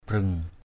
/brøŋ˨˩/ (d.) dây mấu = liane de couleur rouge.